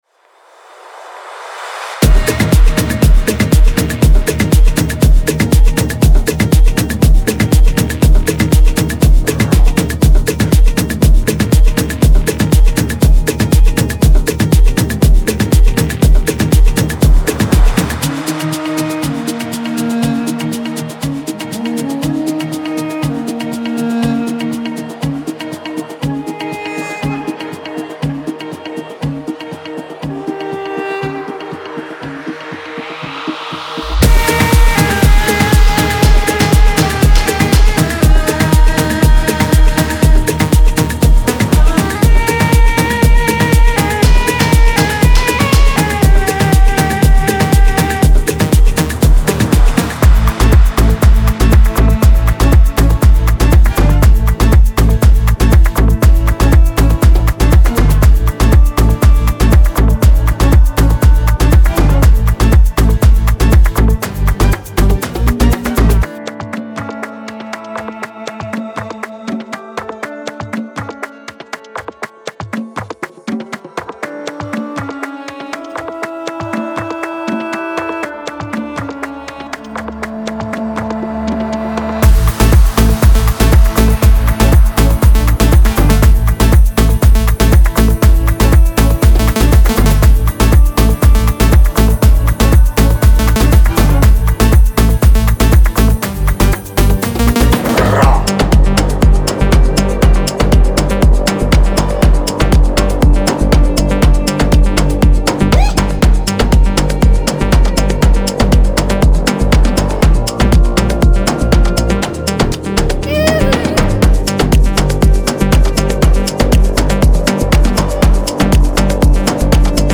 Genre:Afro House
ご注意：オーディオデモは、大音量で圧縮され、均一に聴こえるよう処理されています。
120 BPM